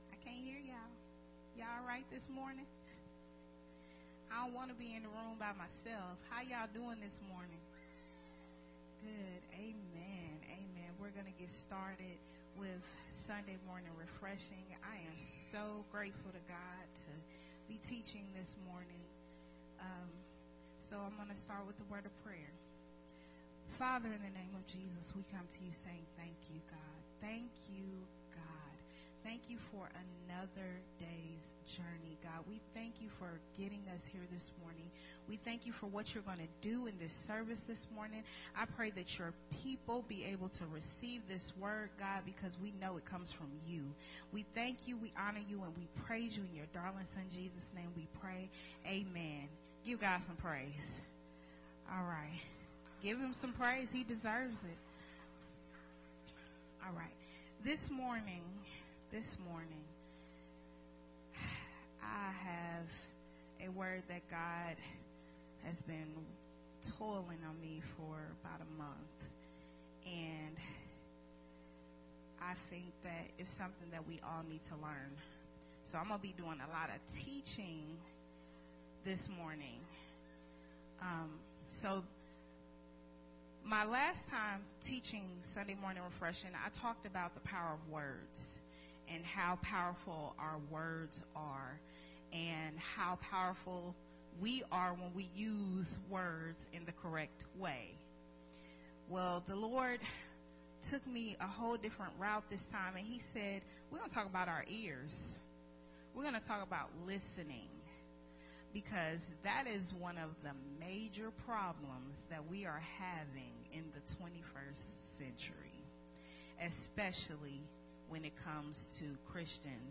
recorded at Unity Worship Center on October 31, 2021.